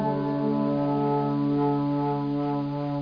1 channel
flutevi.mp3